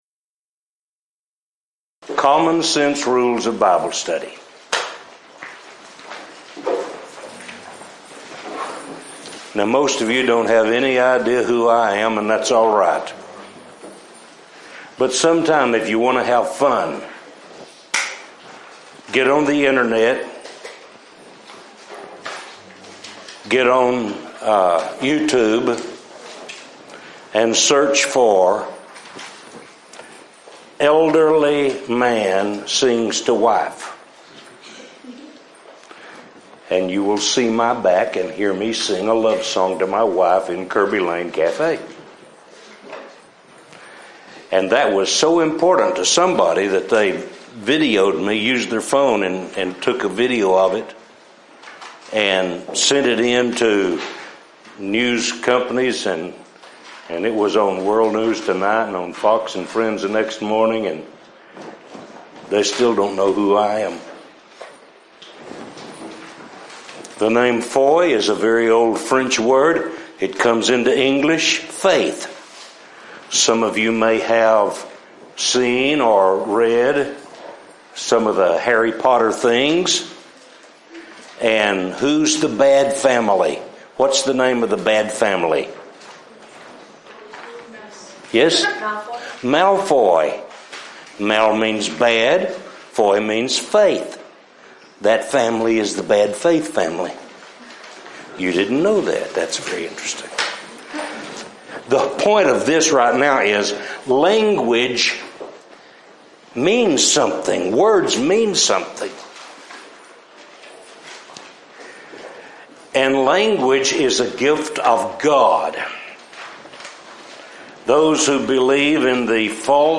Event: 2015 Discipleship University
Youth Sessions